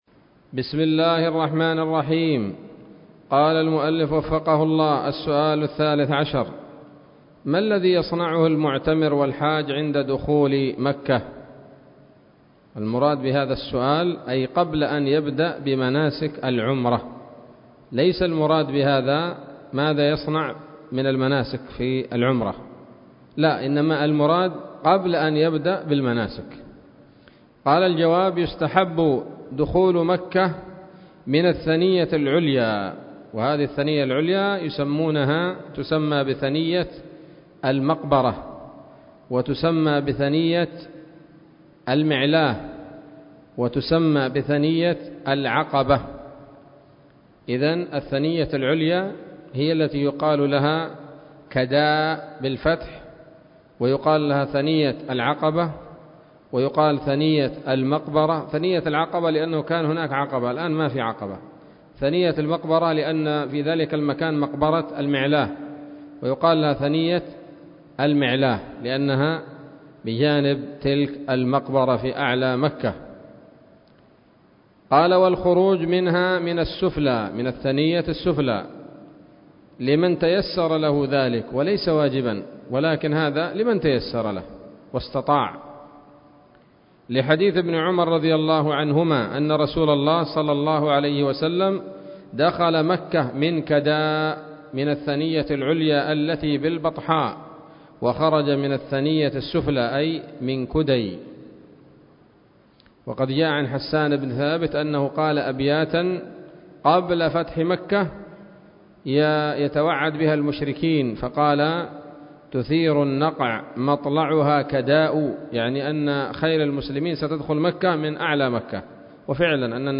الدرس الثاني عشر من شرح القول الأنيق في حج بيت الله العتيق